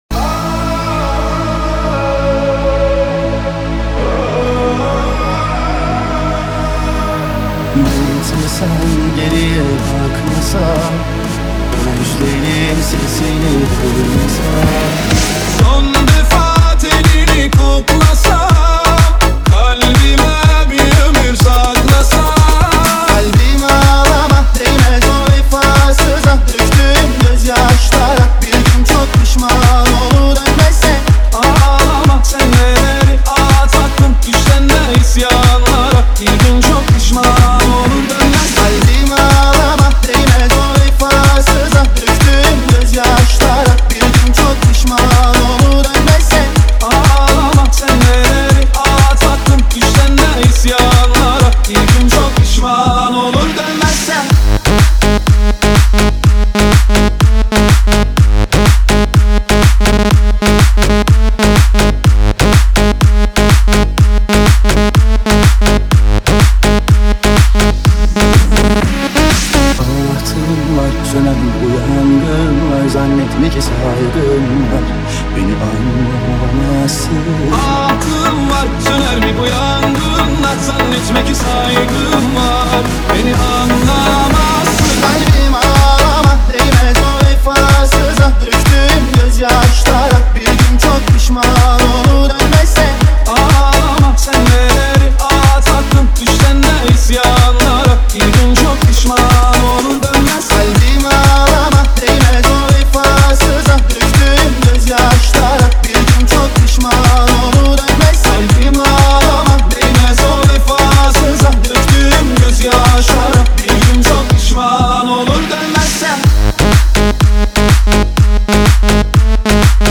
دانلود نسخه ریمیکس همین موزیک